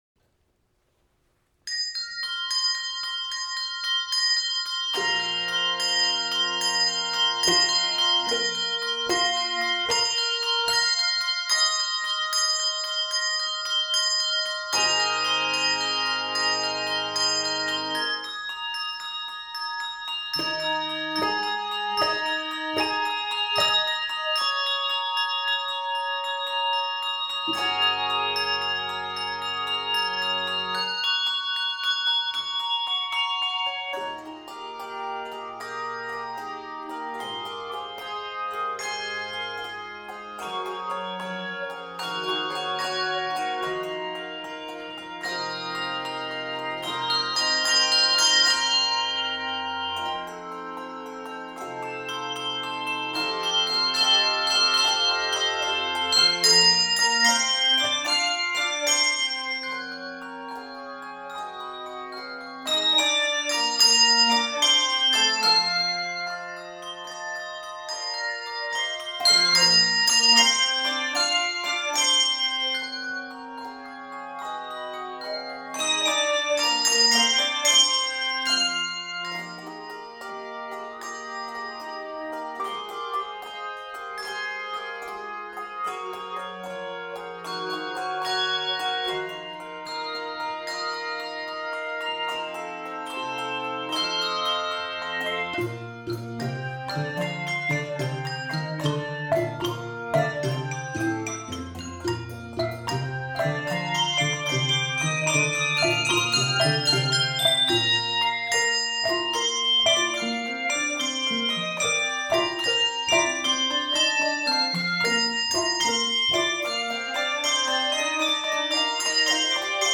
handbell music
played delicately on optional auxillary bells